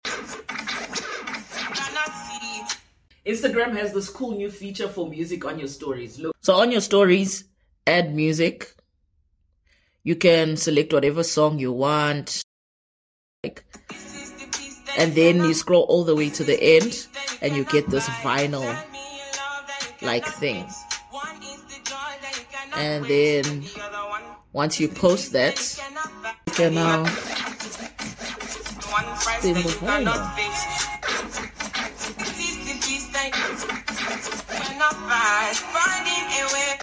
Cool new vinyl scratch feature sound effects free download